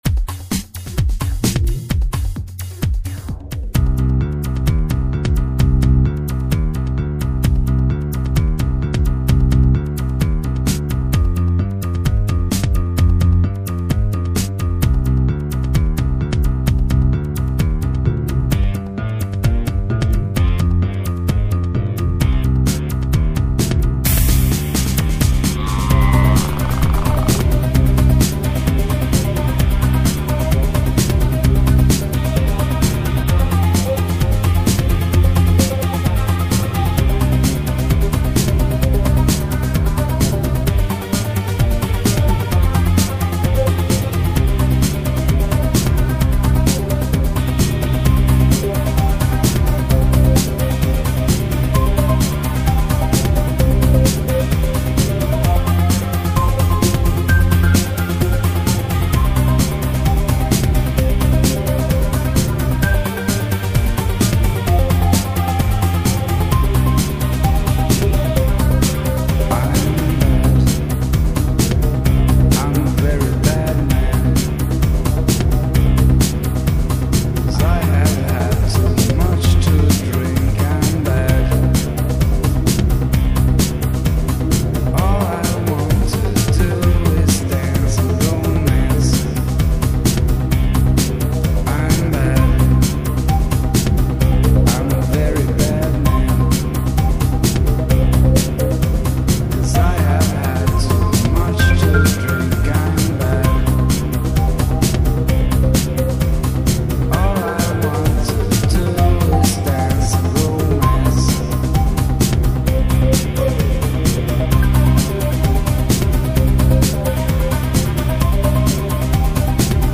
(House)